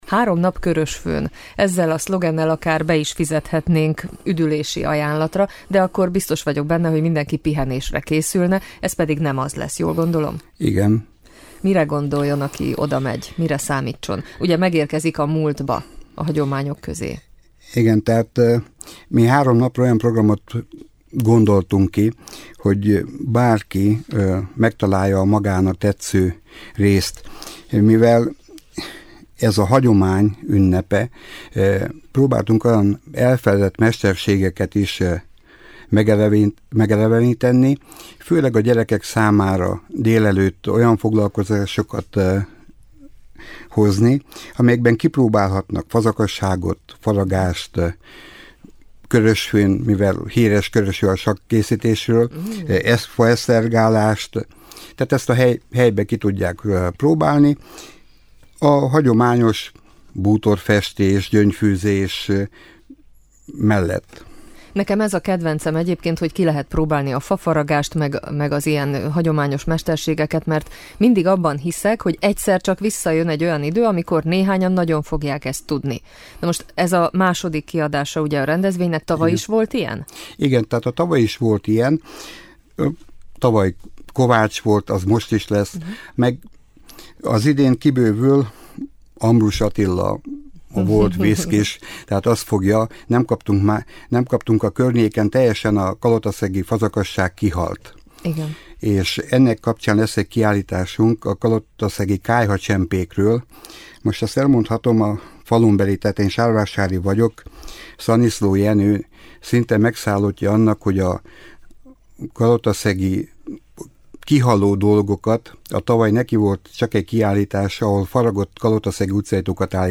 volt a vendégünk a stúdióban